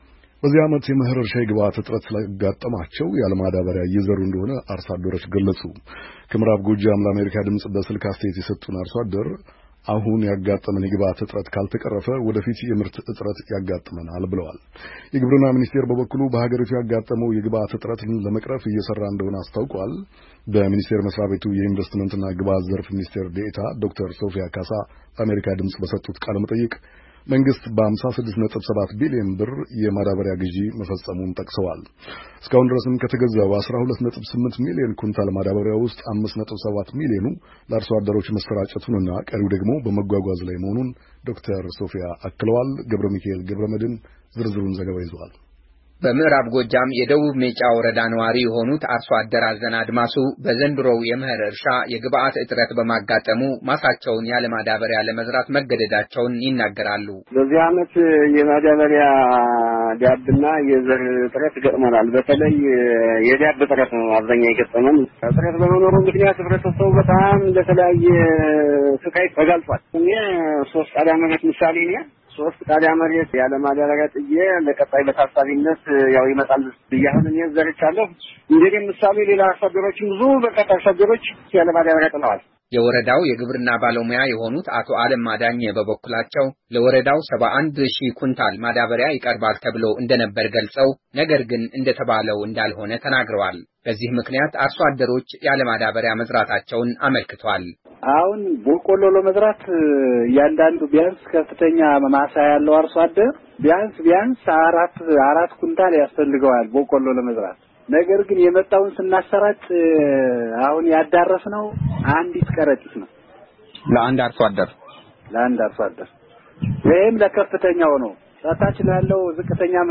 ከዐማራ ክልል ምዕራብ ጎጃም ዞን ሜጫ ወረዳ፣ ለአሜሪካ ድምፅ በስልክ አስተያየት የሰጡ አርሶ አደር፣ የግብአት እጥረት በማጋጠሙ፣ ማሳቸውን ያለማዳበሪያ ለመዝራት መገደዳቸውን ይናገራሉ፡፡ እጥረቱ ካልተቀረፈም፣ ወደፊት የምርት እጥረት እንዳይገጥማቸው ስጋቸውን አመልክተዋል፡፡